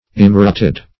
immerited - definition of immerited - synonyms, pronunciation, spelling from Free Dictionary Search Result for " immerited" : The Collaborative International Dictionary of English v.0.48: Immerited \Im*mer"it*ed\, a. Unmerited.